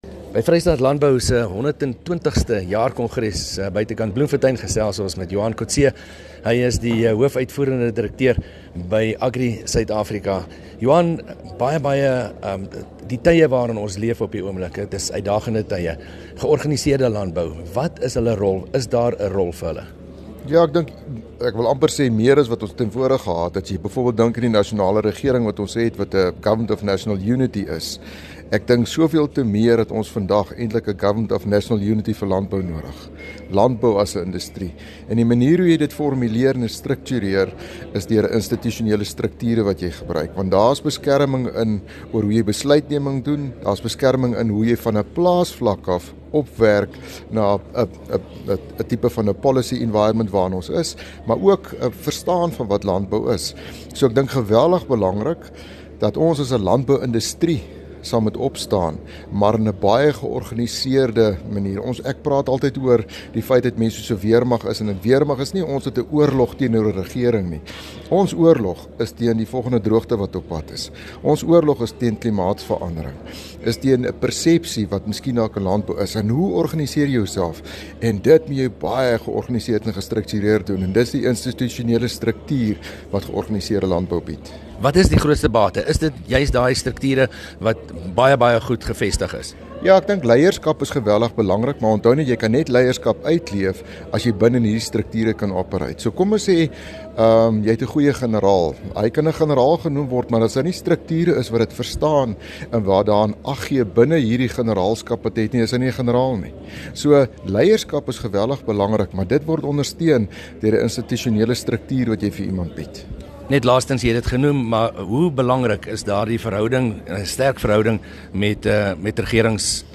by die Vrystaat Landbou kongres in Bloemfontein